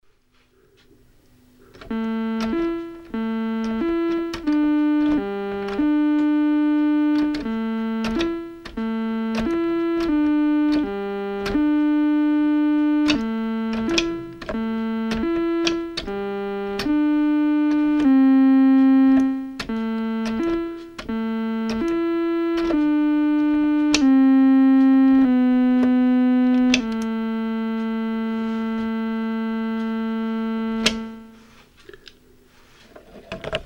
Me having a play with the keys... one finger action.